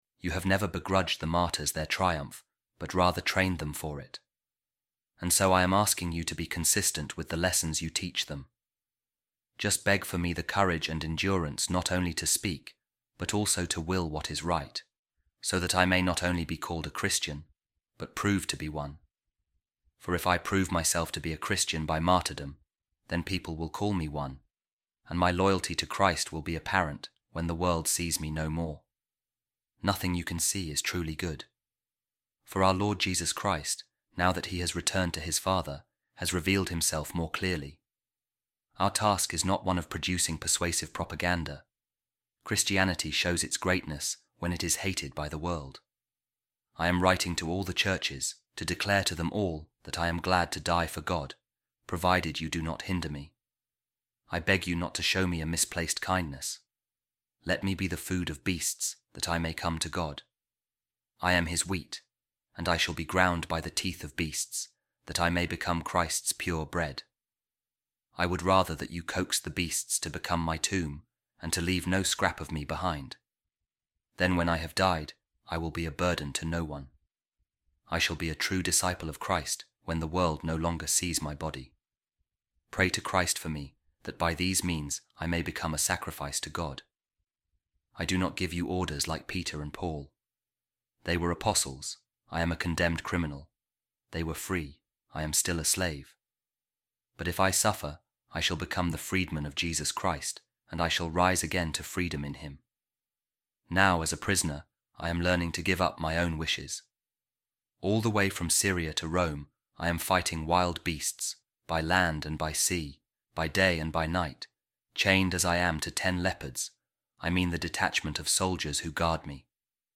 Office Of Readings | Ordinary Time Week 10, Monday | A Reading From The Letter Of Saint Ignatius Of Antioch To The Romans | Christian Witness | Martyrdom